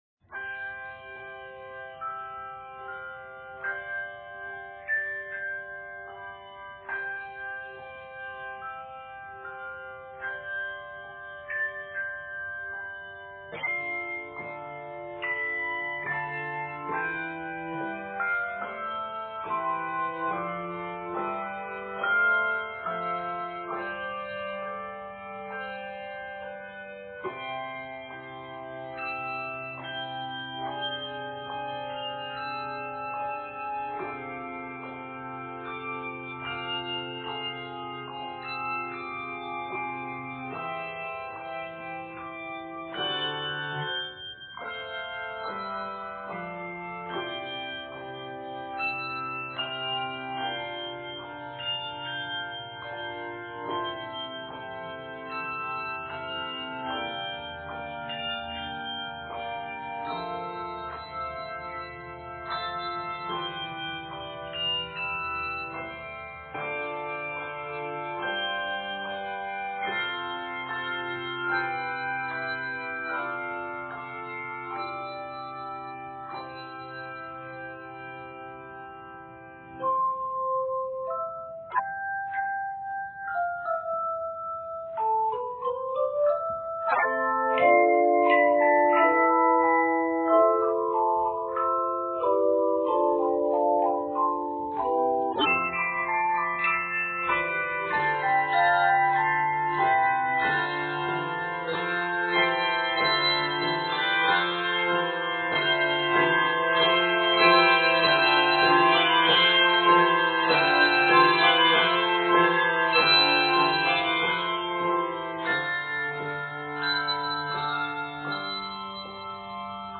Clear and uncomplicated
Octaves: 3-5